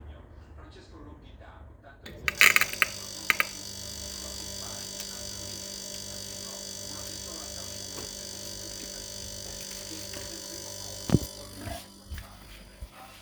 Rumore
Per quanto riguarda il rumore, sembrerebbe la formazione di un arco elettrico (una "scintilla").
Frigo.m4a